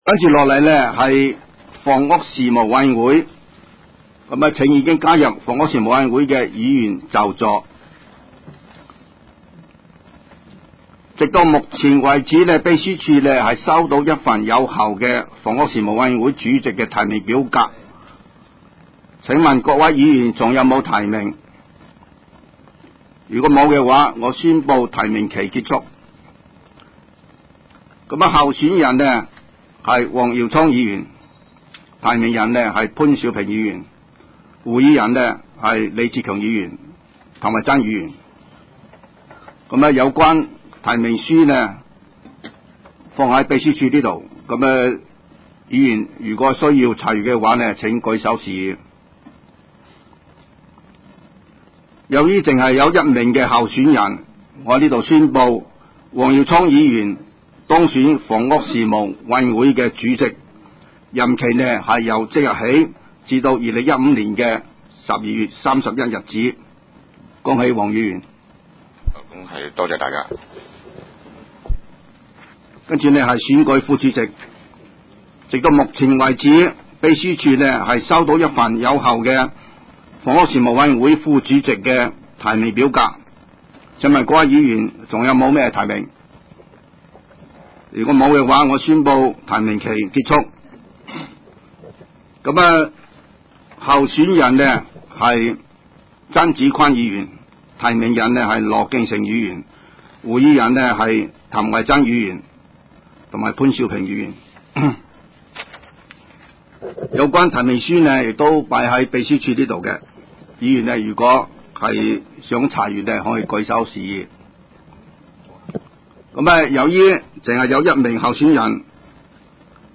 委员会会议的录音记录
葵青民政事务处会议室